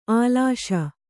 ♪ ālāṣa